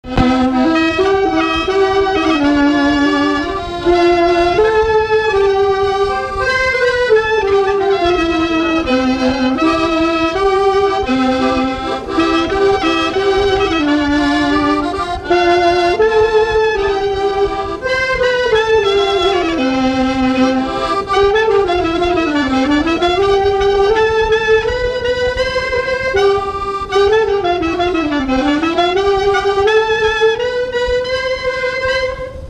Chaillé-sous-les-Ormeaux
Résumé instrumental
Pièce musicale inédite